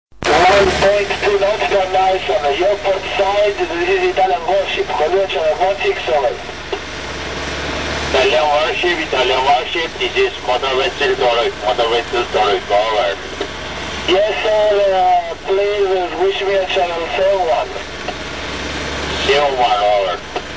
Predvečer imali predstavu na VHF u režiji "US warshipa".